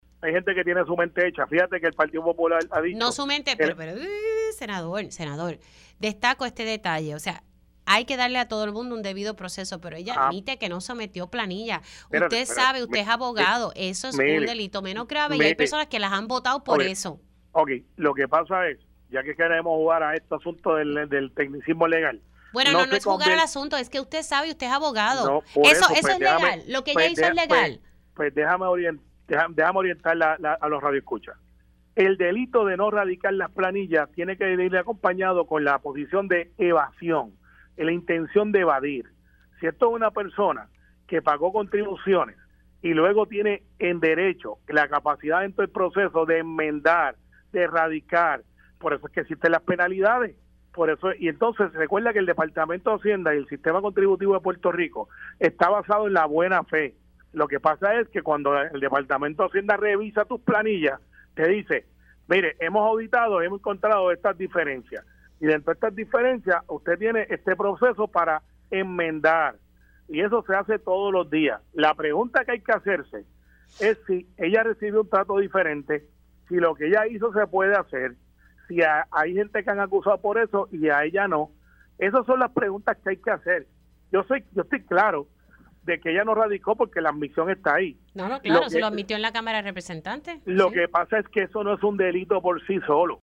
315-CARMELO-RIOS-SENADOR-PNP-NO-RADICAR-PALNILLAS-NO-ES-UN-DELITO-POR-SI-SOLO.mp3